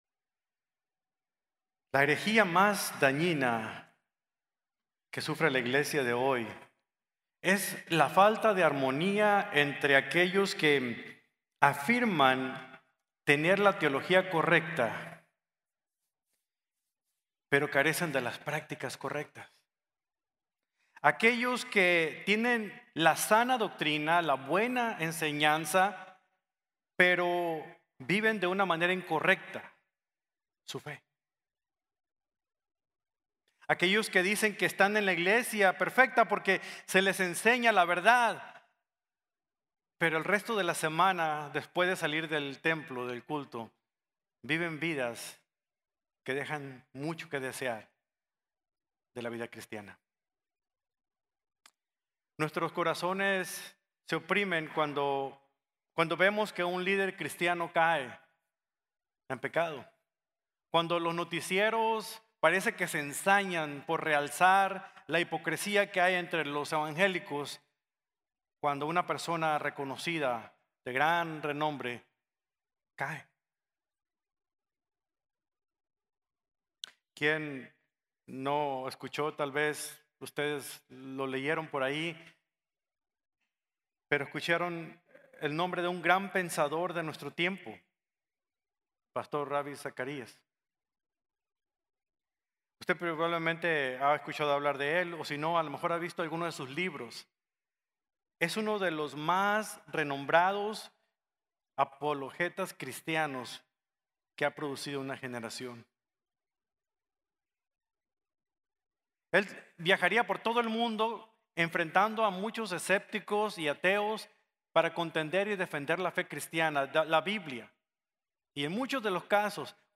Colosenses 1:13-23 | Sermón | Iglesia Bíblica de la Gracia